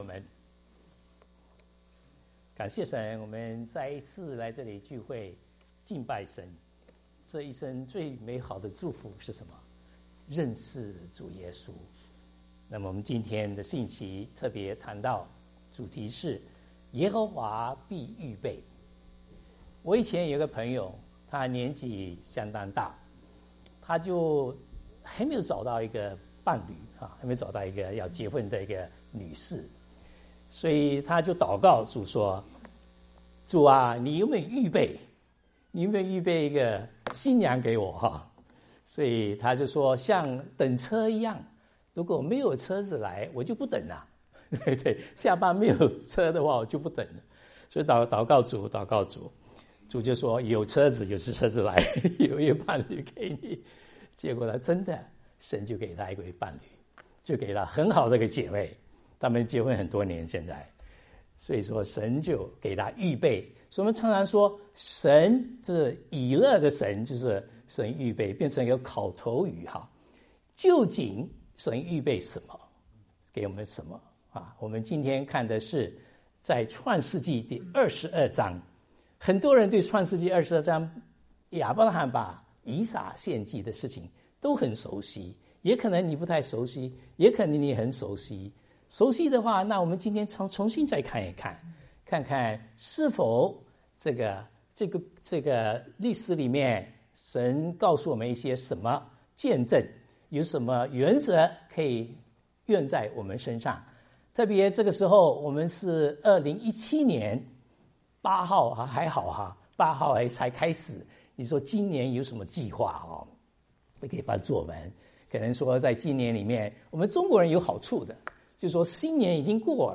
題目： “ 耶和華必預備 ” 講員